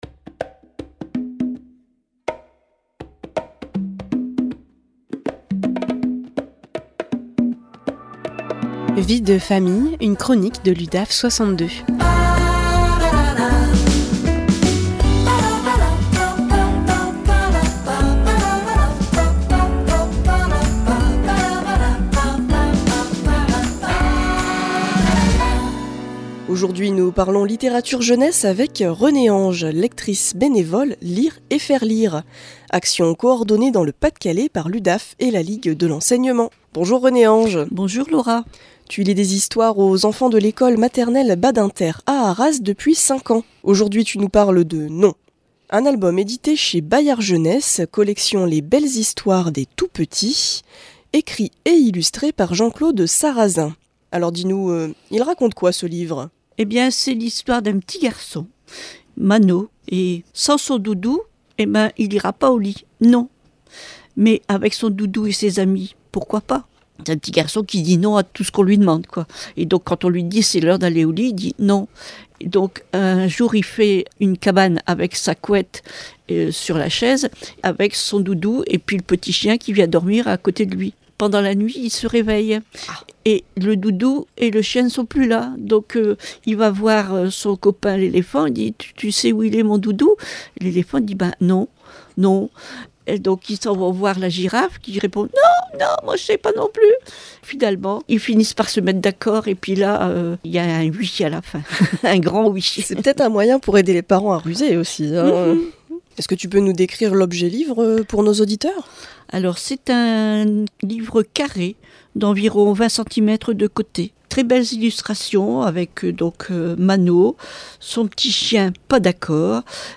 Vie de Famille, une chronique de l’Udaf62 en live sur RADIO PFM 99.9